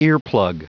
Prononciation audio / Fichier audio de EARPLUG en anglais
Prononciation du mot : earplug